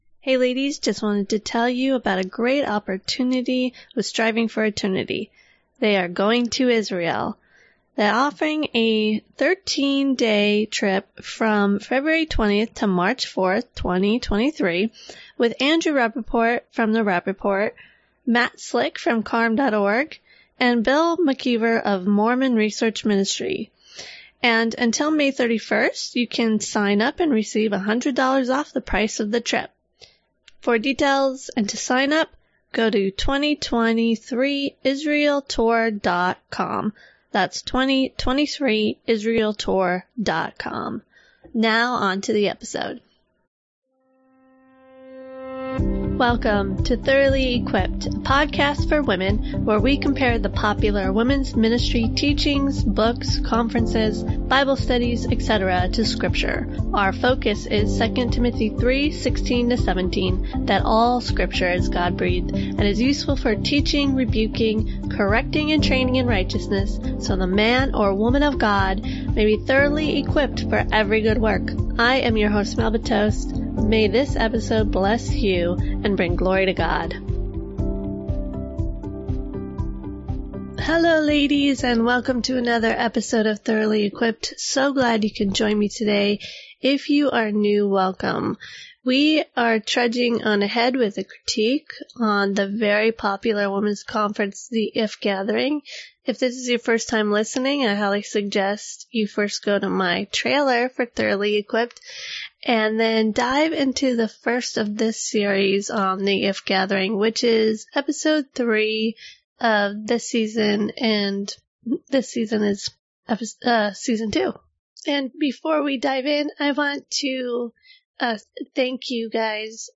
Sorry for the quality of the audio.